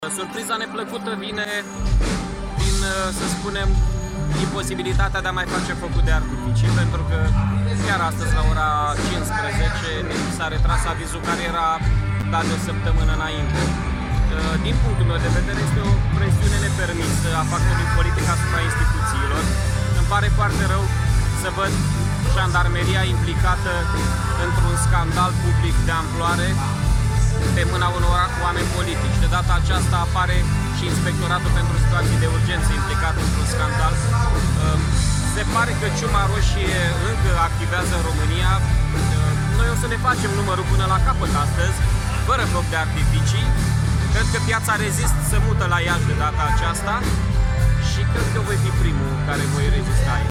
Primarul Mihai Chirica într-o declarație facută aseara in timpul concertului